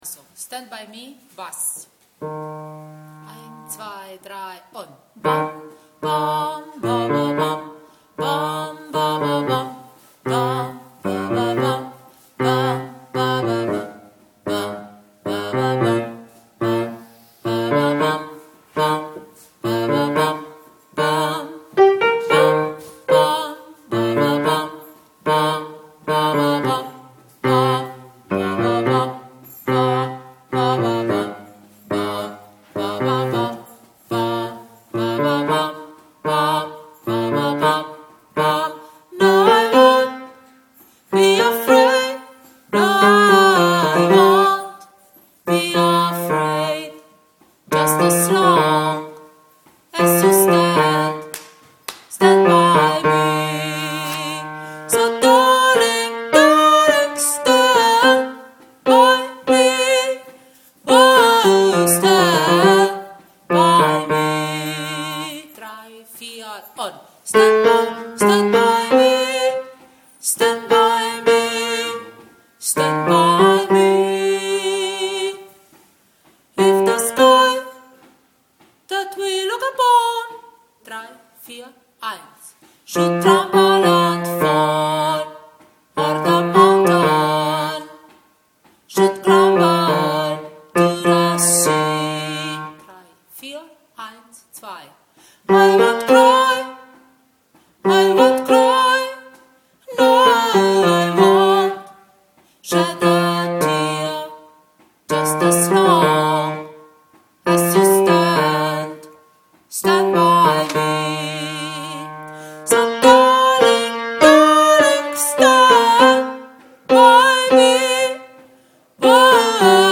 Stand-by-me-Bass.mp3